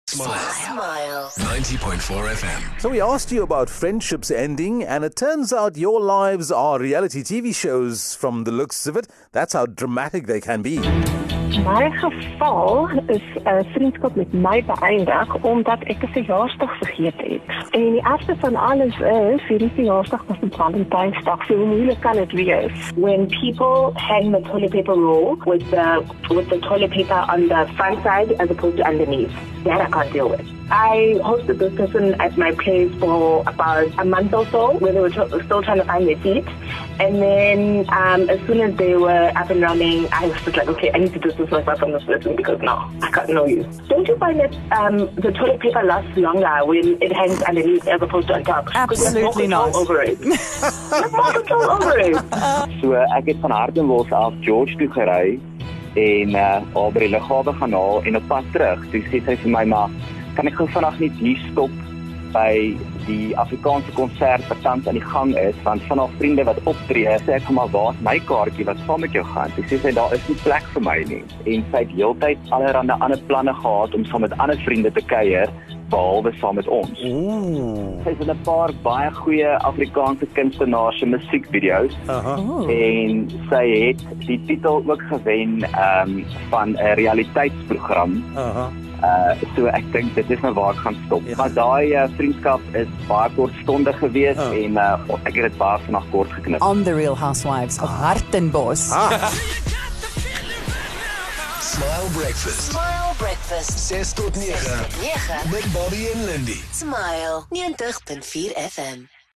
We read that in a survey 1 in 10 people would end a friendship over social media content. The Smile Breakfast team got talking about some reasons we'd end things with a friend for silly reasons and some callers really have a bit of drama in their lives.